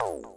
menuback.wav